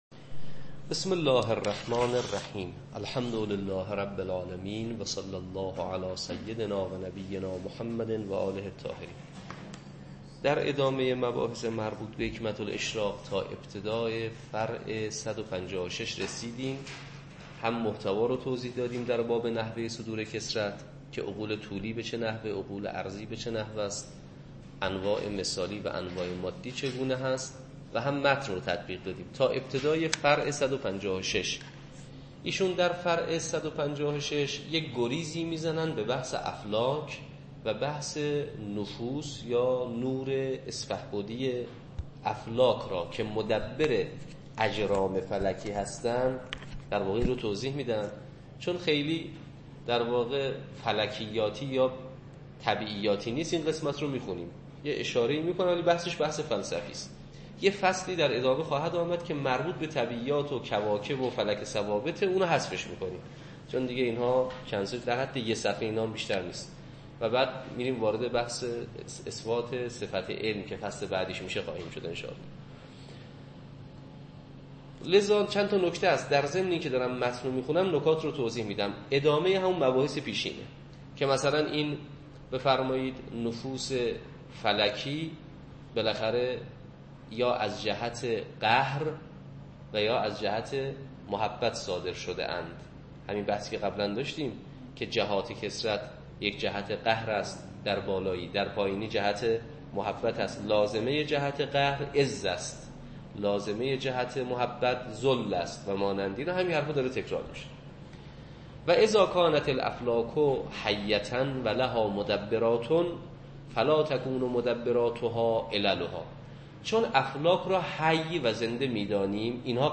حکمه الاشراق - تدریس